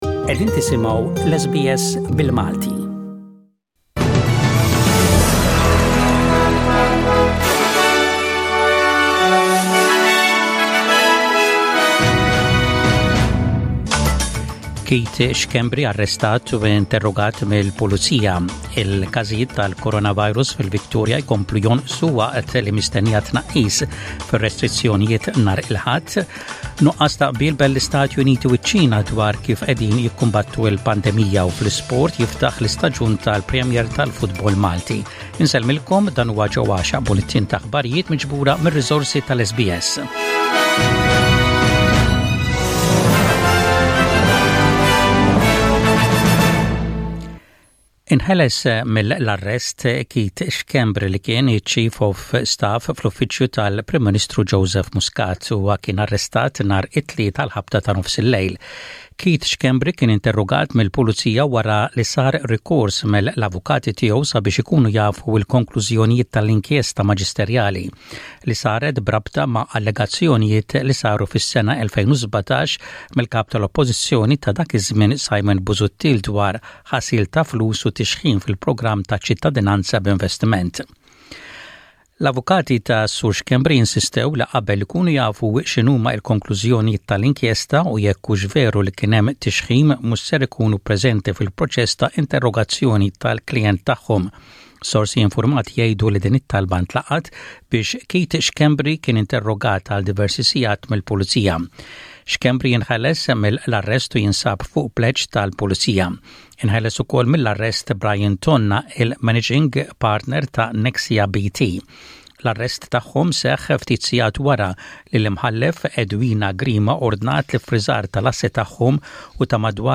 SBS Radio | Maltese News: 25/09/20